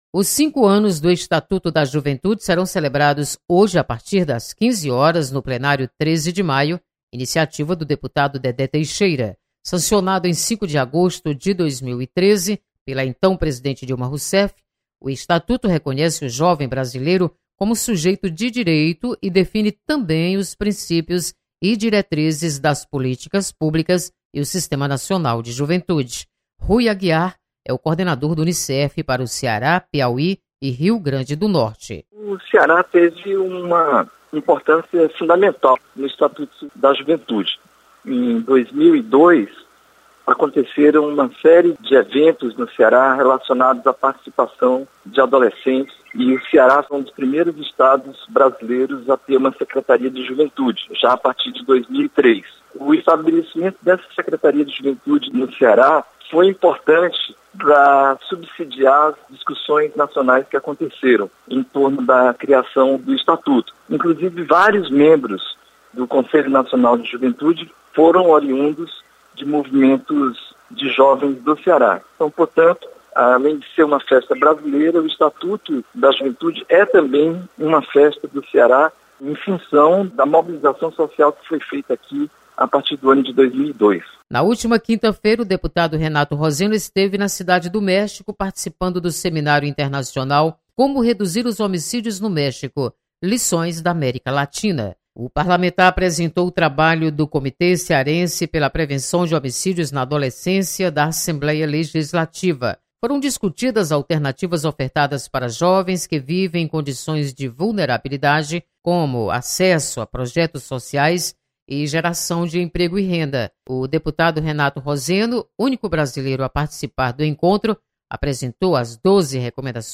Deputados destacam importância do Estatuto da Juventude. Repórter